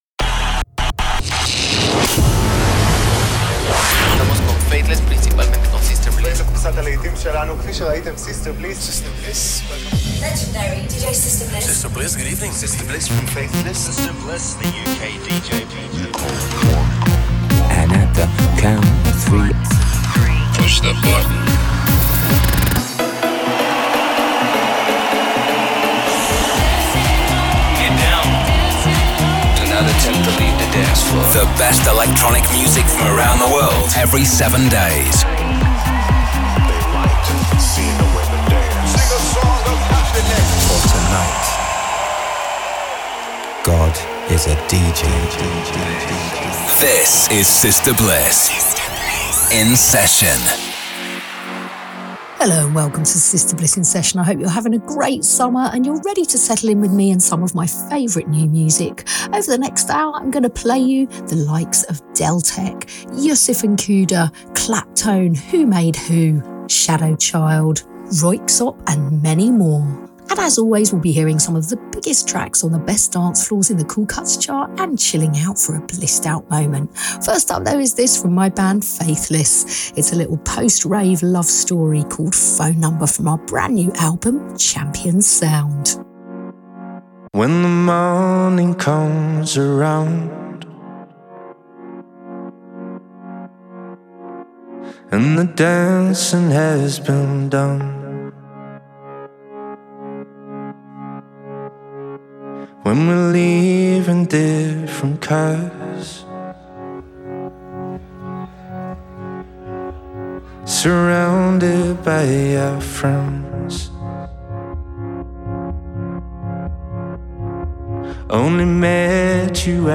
modern dance music
electronic music
a live DJ mix, A List artists and DJs on the phone